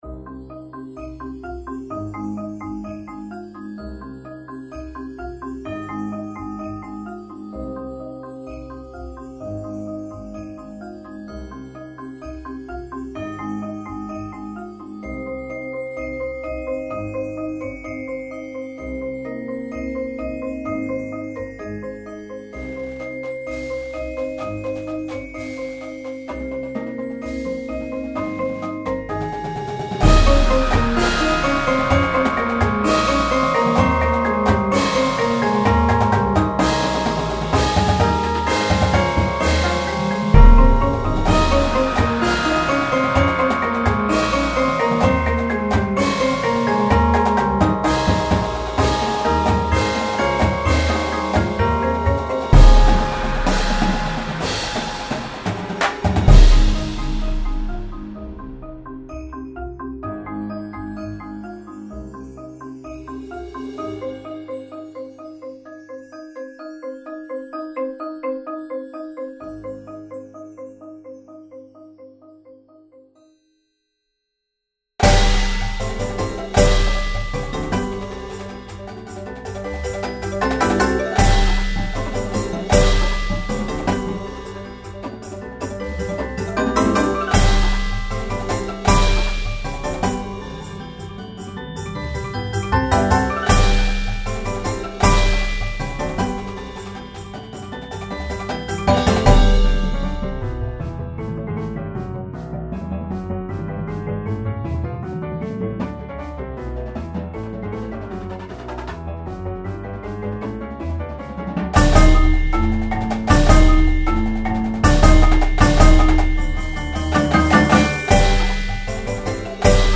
Instrumentation:
Snares
Tenors (Quints)
Bass Drums (4)
Cymbals
Marimba 1, 2
Vibes
Xylo/Chimes
Glockenspiel
Synth
Auxiliary Percussion 1, 2, 3